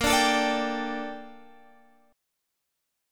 Listen to A#M13 strummed